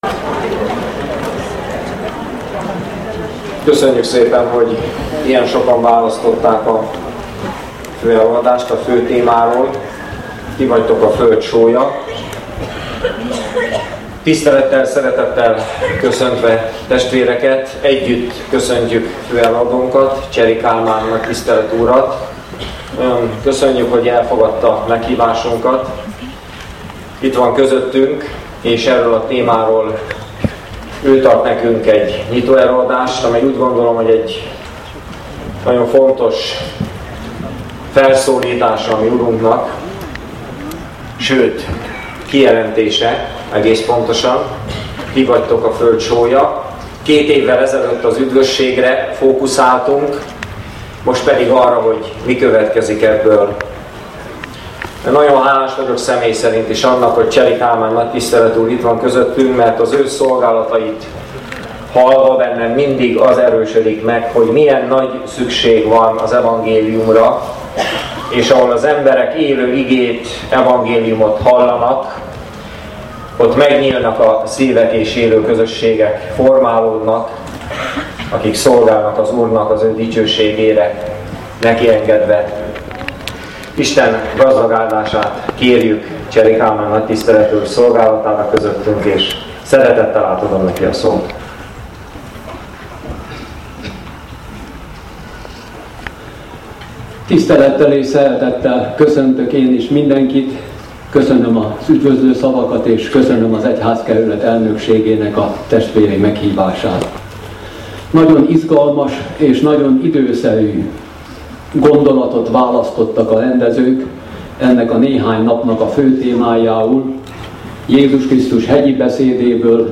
főelőadása